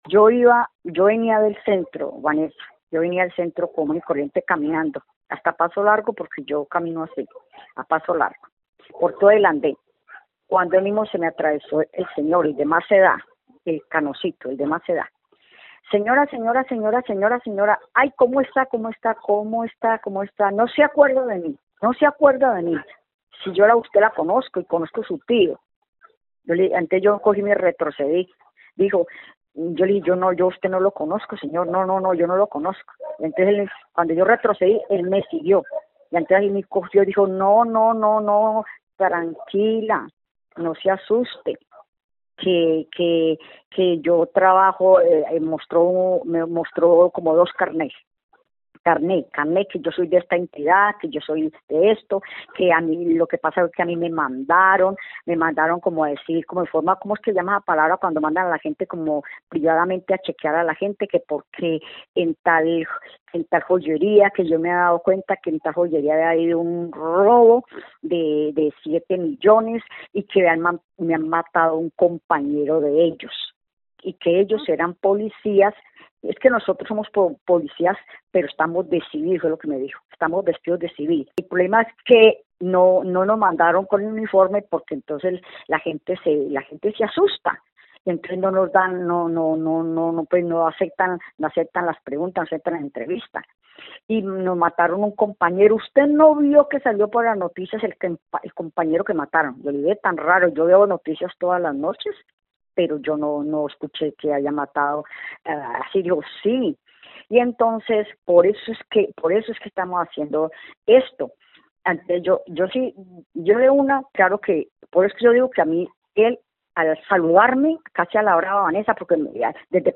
Informe denuncia hurto en Armenia